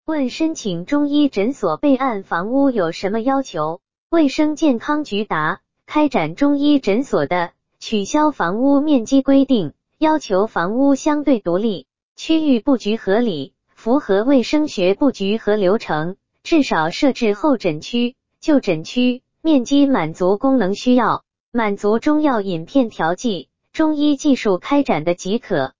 语音播报